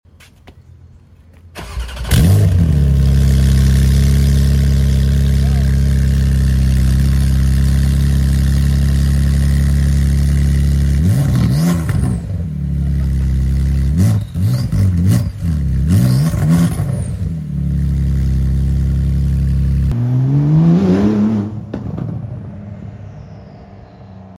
992 Turbo S Full Exhaust sound effects free download
992 Turbo S Full Exhaust ASMR